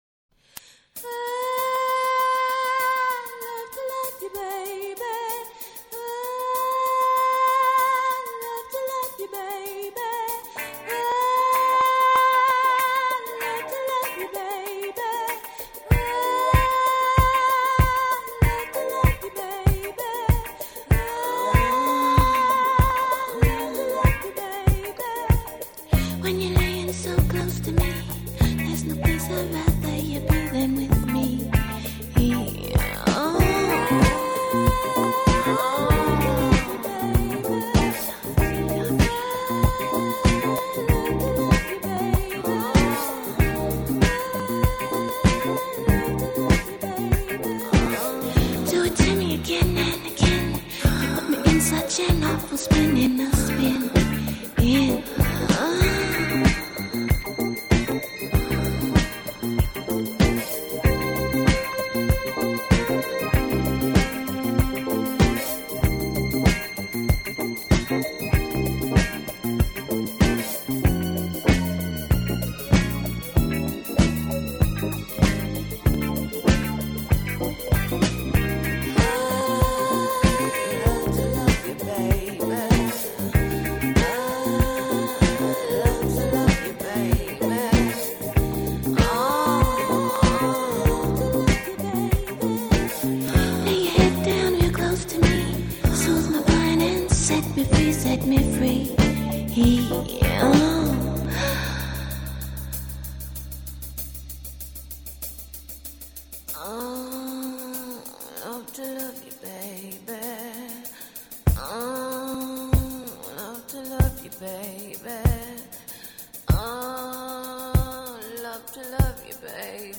Disco 70's